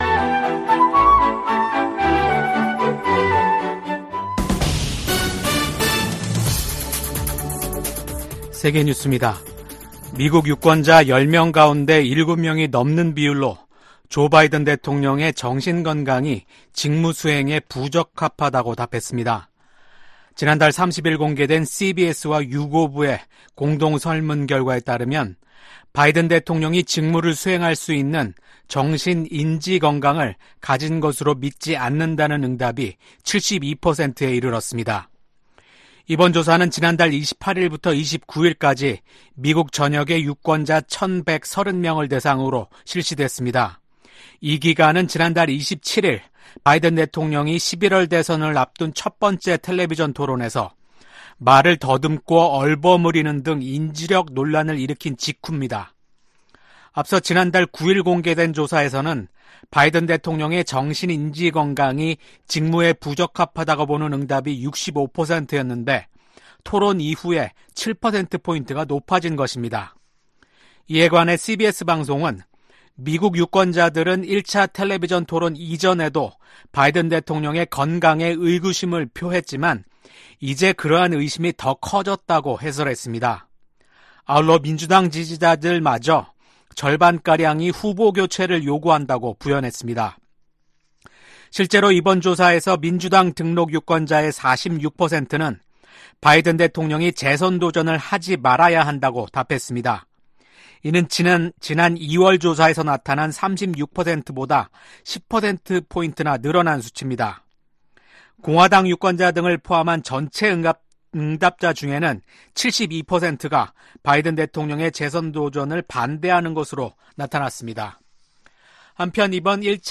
VOA 한국어 아침 뉴스 프로그램 '워싱턴 뉴스 광장' 2024년 7월 2일 방송입니다. 북한이 한반도 시각 1일 탄도미사일 2발을 발사했다고 한국 합동참모본부가 밝혔습니다. 유엔 안보리가 공식 회의를 열고 북한과 러시아 간 무기 거래 문제를 논의했습니다. 북러 무기 거래 정황을 노출했던 라진항에서 또다시 대형 선박이 발견됐습니다.